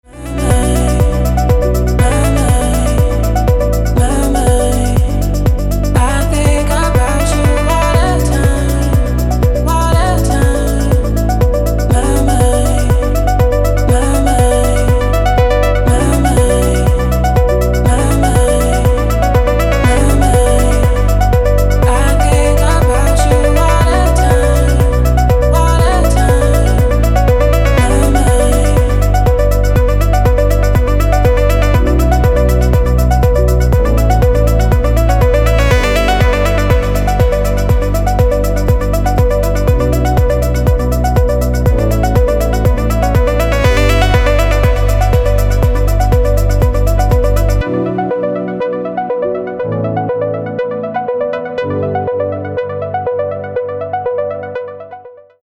Progressive House / Organic House